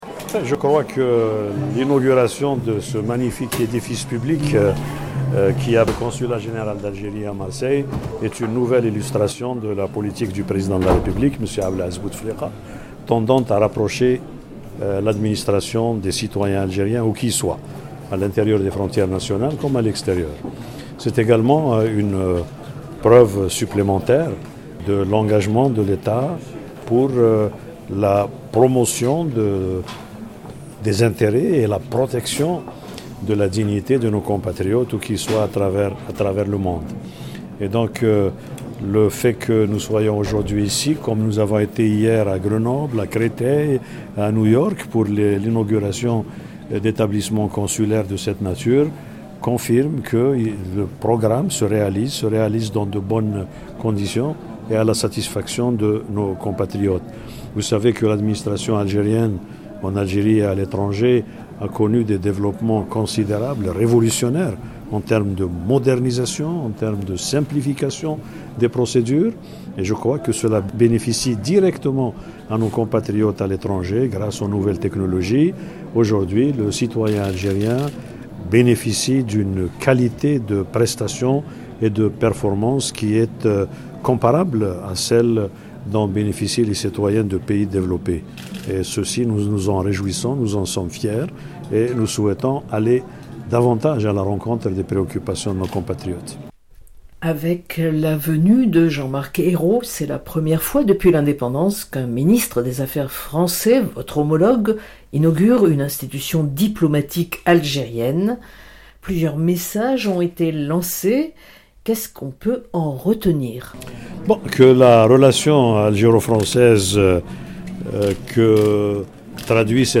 Puis, Ramtane Lamamra parlera de développement économique et de la jeunesse (70% de la population algérienne ) et de la création d’un Conseil supérieur de la jeunesse. Entretien.